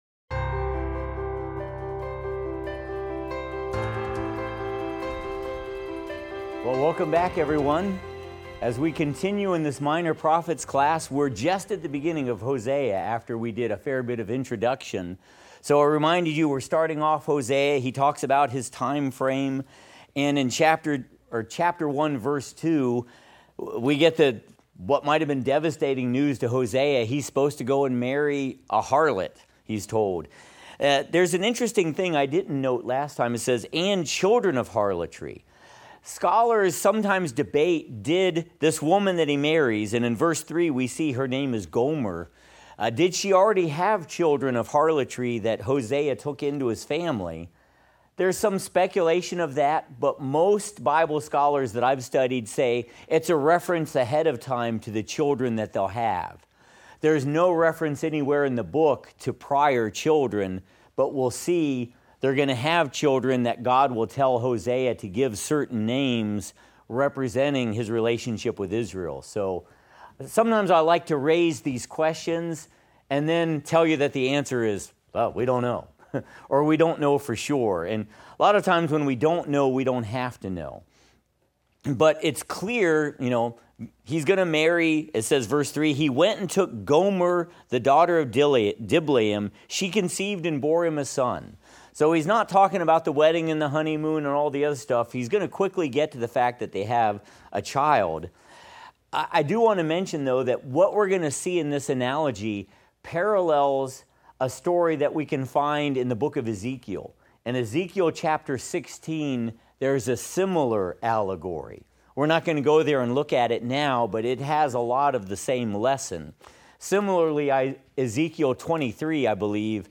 Minor Prophets - Lecture 2 - audio.mp3